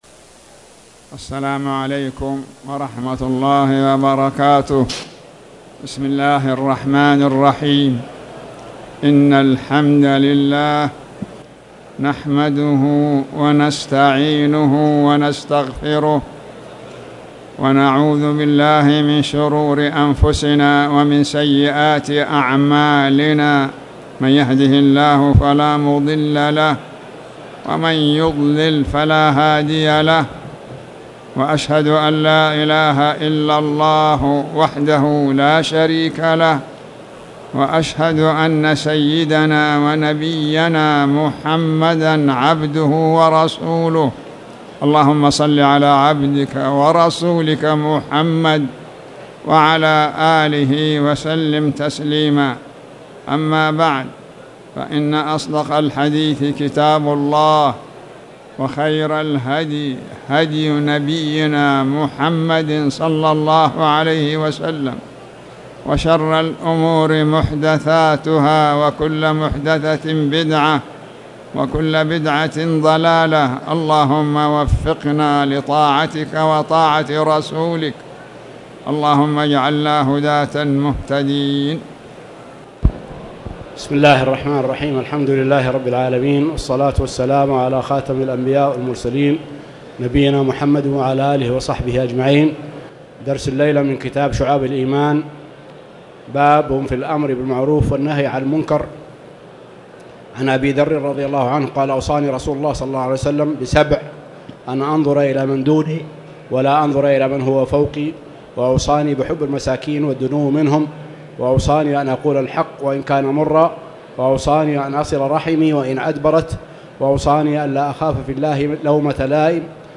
تاريخ النشر ١٤ رجب ١٤٣٨ هـ المكان: المسجد الحرام الشيخ